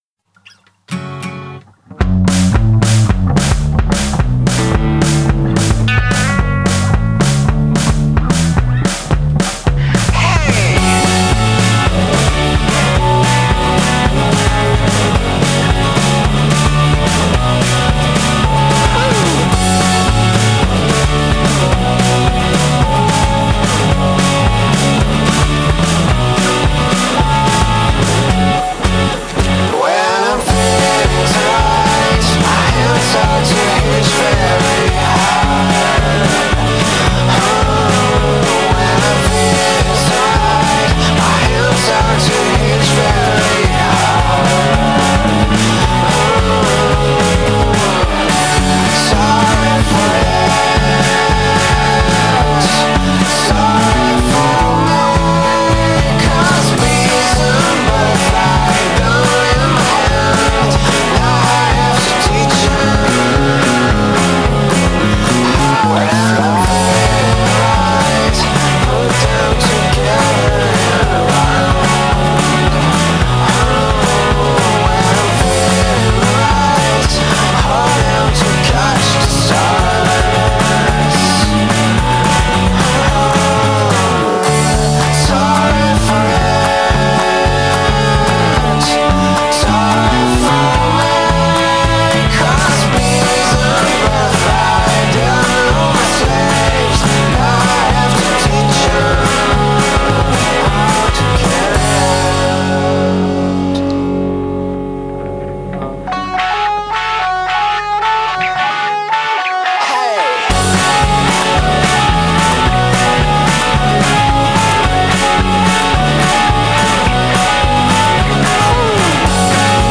The song about bees and butterflies is very catchy.